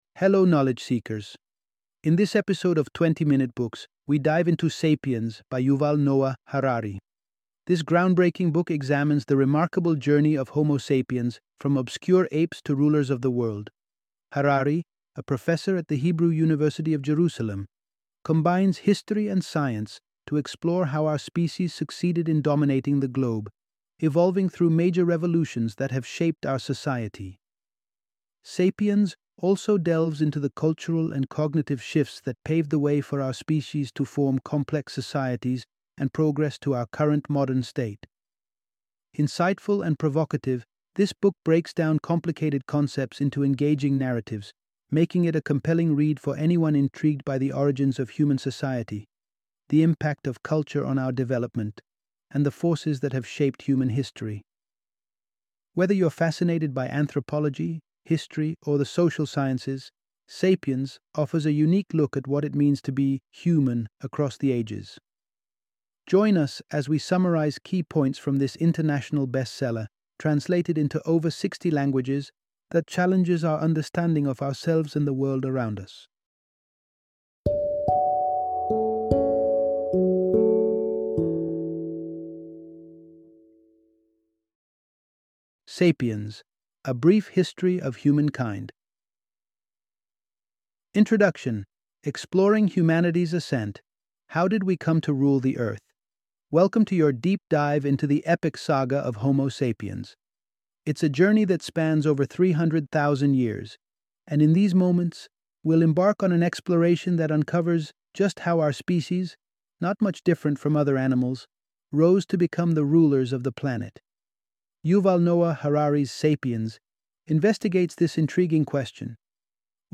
Sapiens - Audiobook Summary